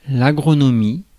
Ääntäminen
Ääntäminen France: IPA: [a.ɡʁɔ.nɔ.mi] Tuntematon aksentti: IPA: /a.ɡʁo.nɔ.mi/ Haettu sana löytyi näillä lähdekielillä: ranska Käännös Substantiivit 1. агрономия Muut/tuntemattomat 2. агроно́мство Suku: f .